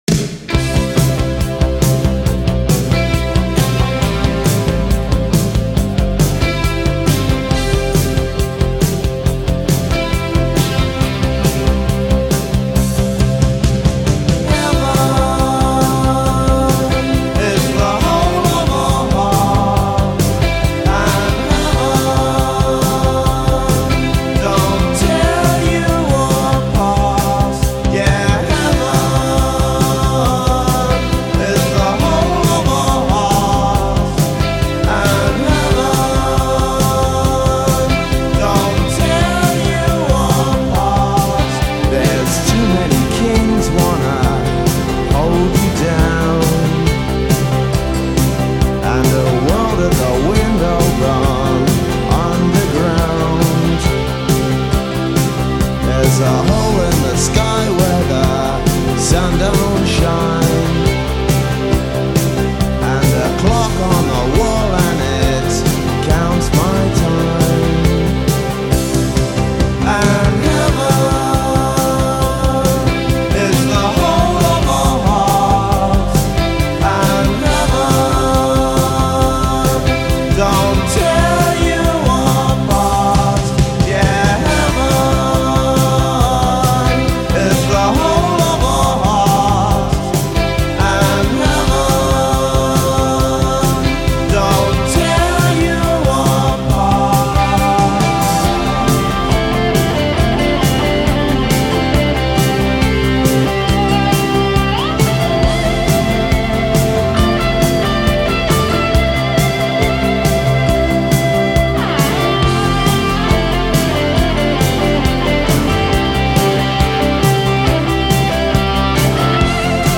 New Wave, Dark, Synthpop, New Romantics, 80’s